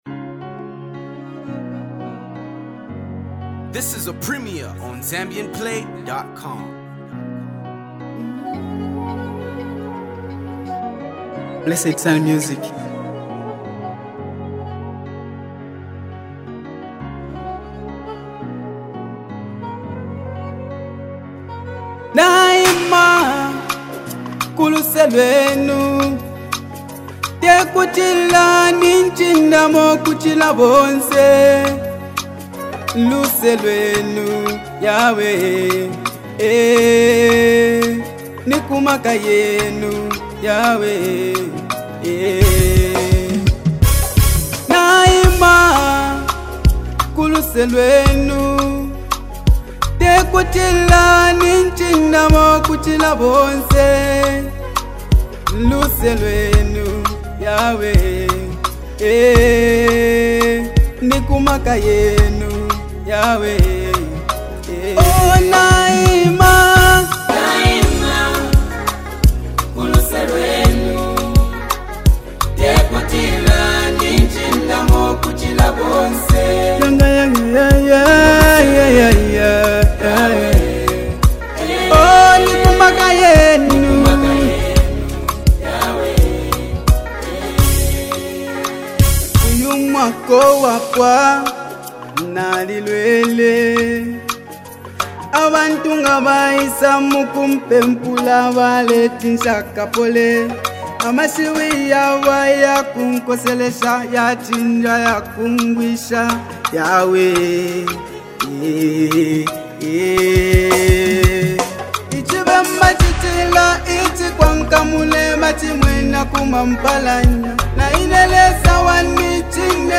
Zambian emerging gospel artist